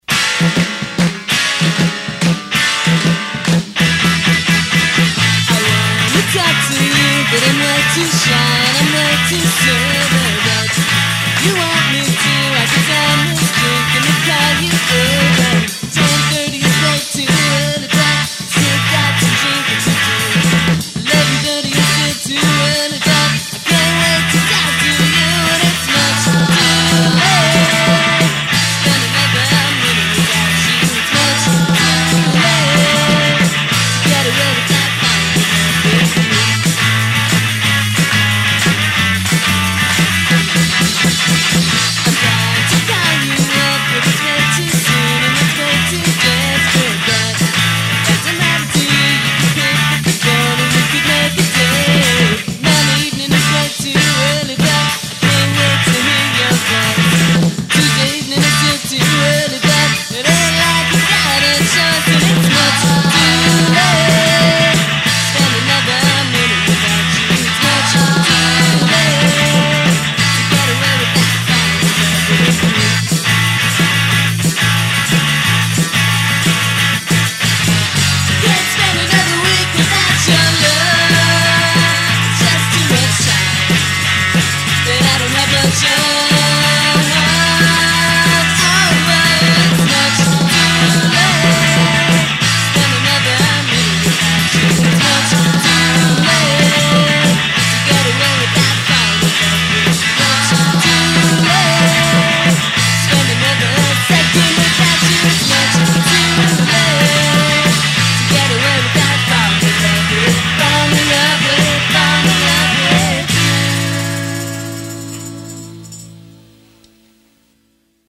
It is lo-fi and jangly!